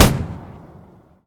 mg-shot-9.ogg